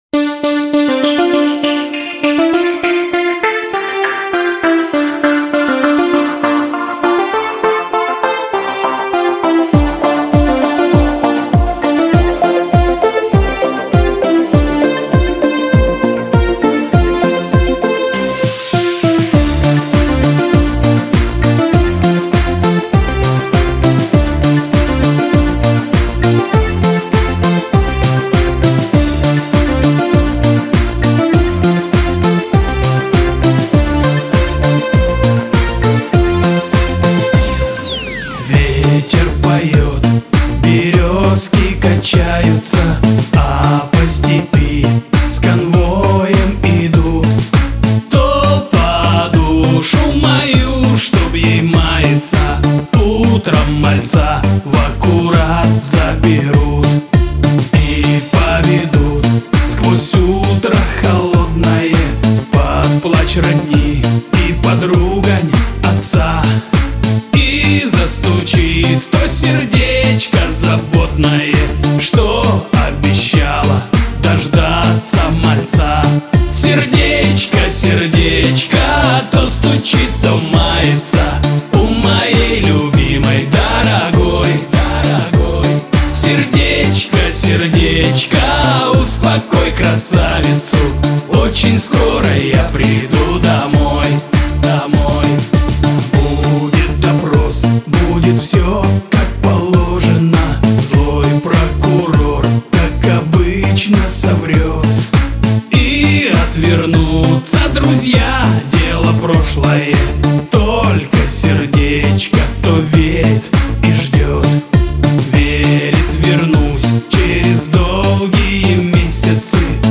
На мобилку » Mp3 » Шансон